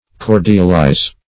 Search Result for " cordialize" : The Collaborative International Dictionary of English v.0.48: Cordialize \Cor"dial*ize\ (k[^o]r"jal*[imac]z or k[^o]rd"yal*[imac]z; 106), v. t. 1.